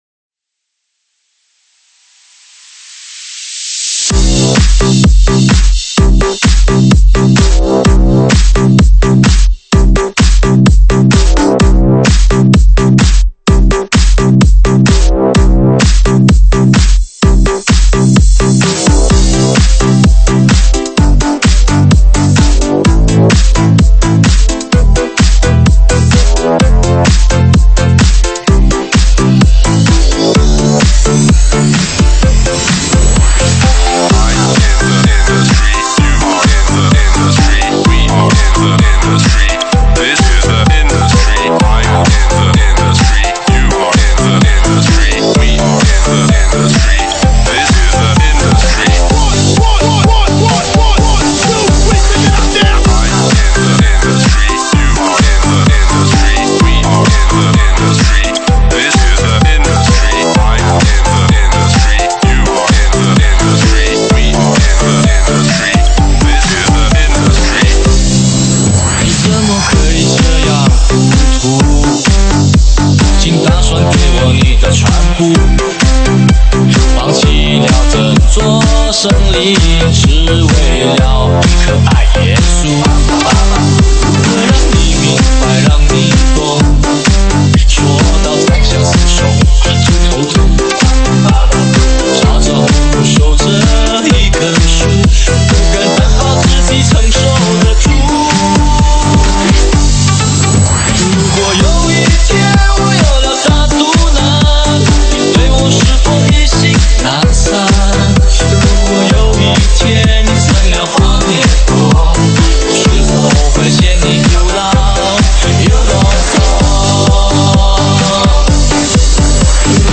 舞曲类别：ProgHouse